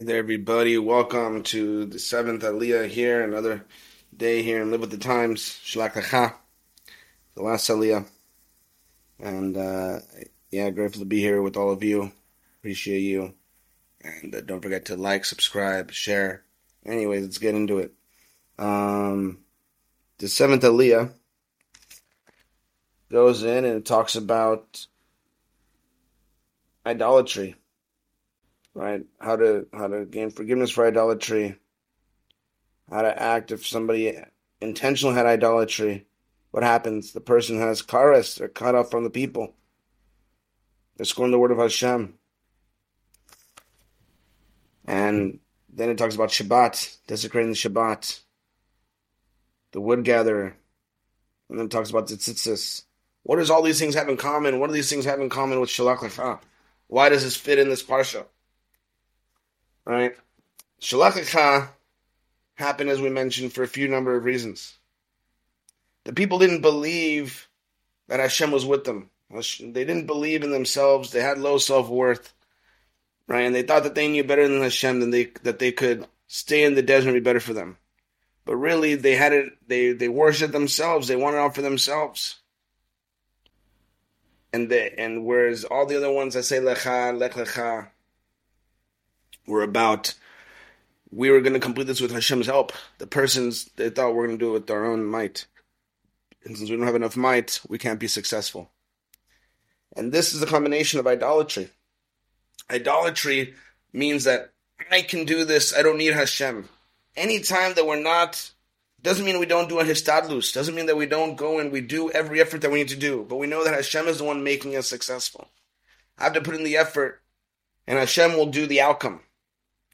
Class Description: